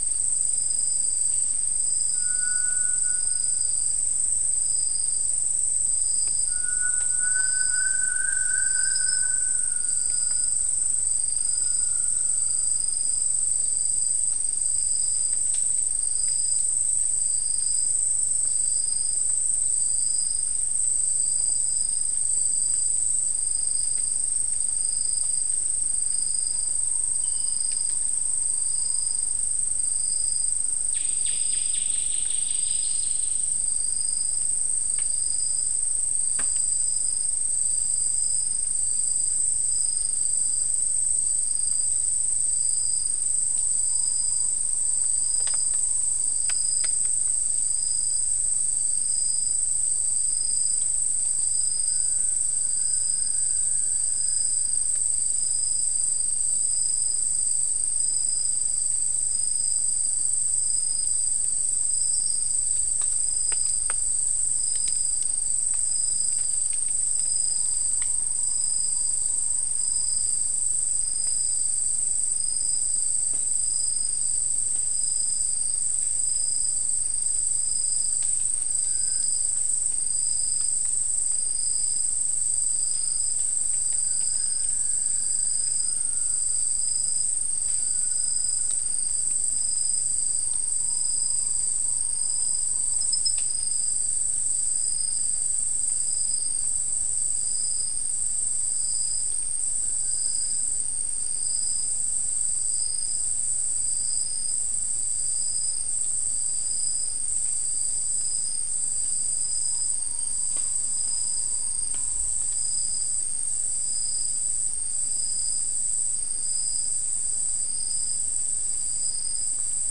Soundscape
Location: South America: Guyana: Kabocalli: 4